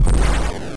51 - Water Boss Shoot